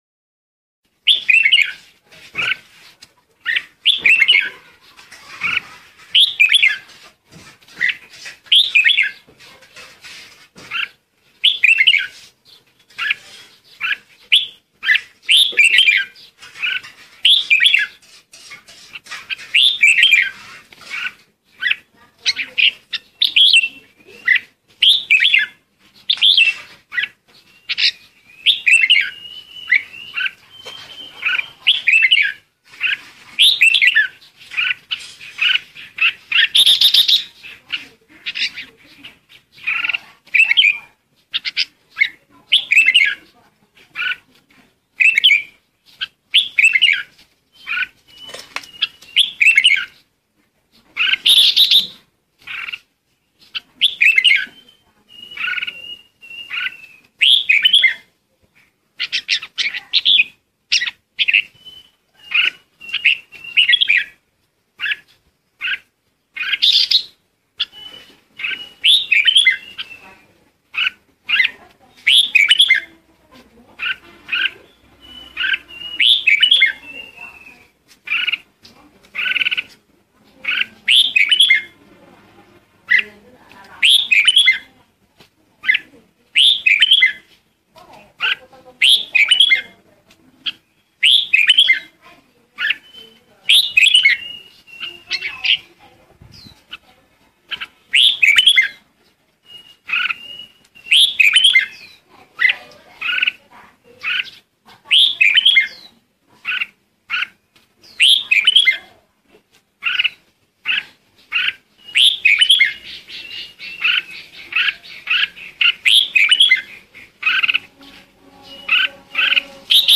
เสียงนกกรงหัวจุกตัวเมียร้องเรียกหาคู่ เสียงชัด100% mp3
หมวดหมู่: เสียงนก
tieng-chim-chao-mao-hot-goi-trong-th-www_tiengdong_com.mp3